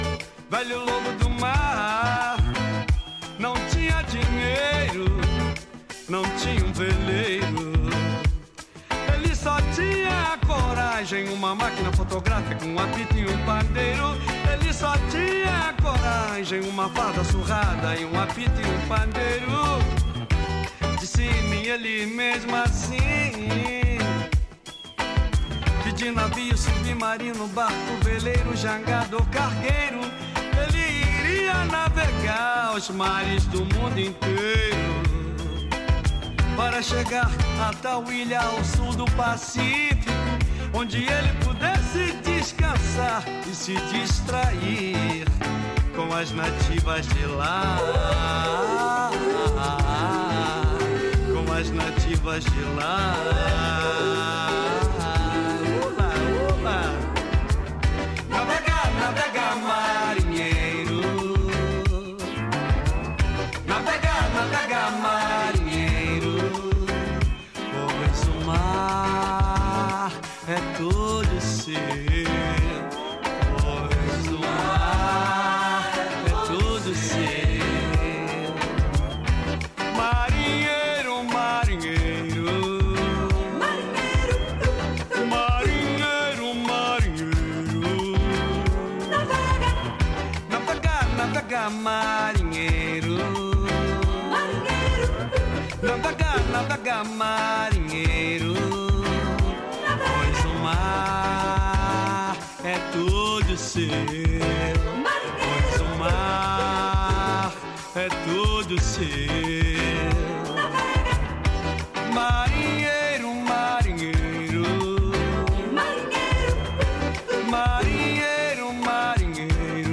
Com um twist mais pop próprio da época
As claps sintéticas
balada boogie